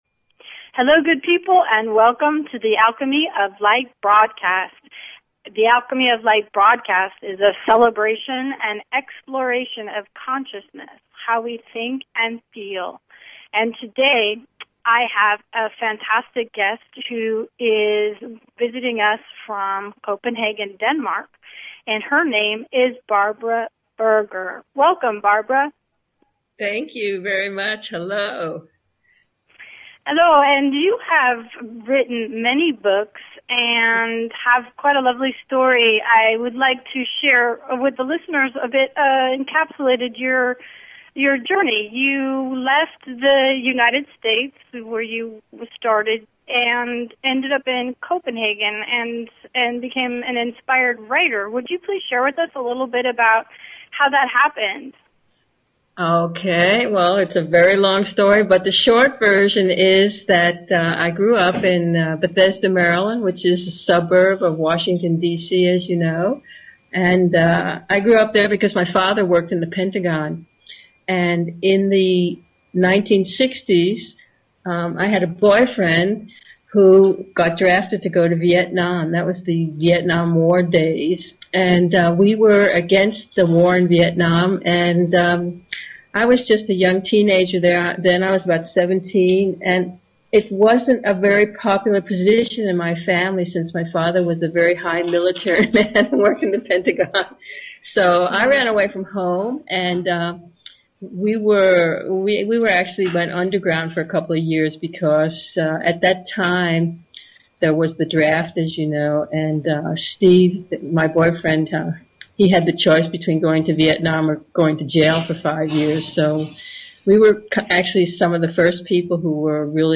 Talk Show Episode, Audio Podcast, Alchemy_of_Light and Courtesy of BBS Radio on , show guests , about , categorized as